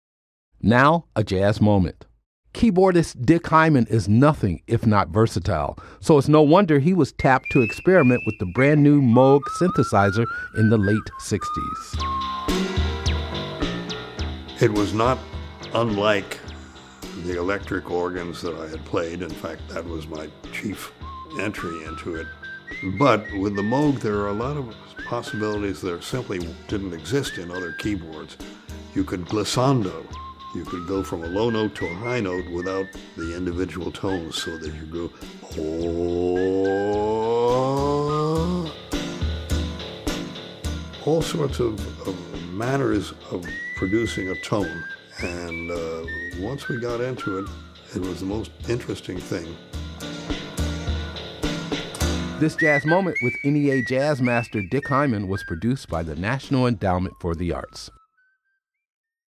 Excerpt of “The Moog and Me” composed and performed by Dick Hyman, from the album Moon Gas, used courtesy of The Omni Recording Corporation and by permission of Eastlake Music Inc. [ASCAP].